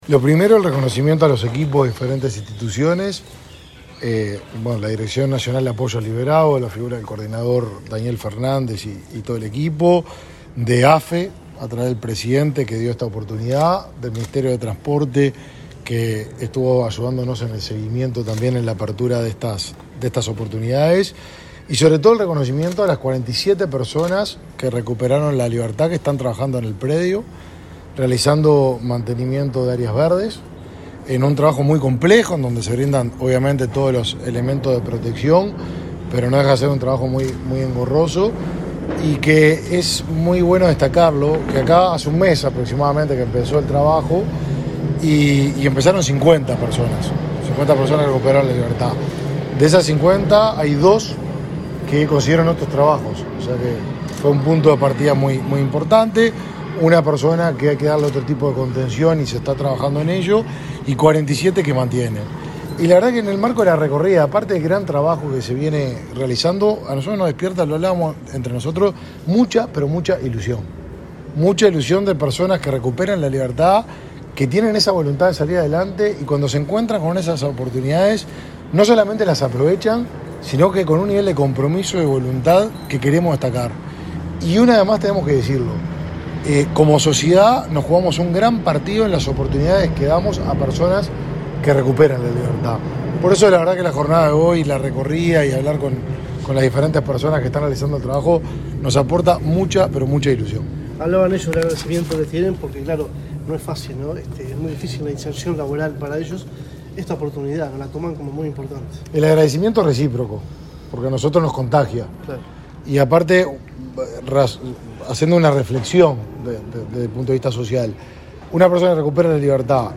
Declaraciones del ministro Martín Lema y el presidente de AFE, José Polak
Este martes 8, el ministro de Desarrollo Social, Martín Lema, recorrió las obras en el predio de AFE de Peñarol, acompañado por el presidente de esa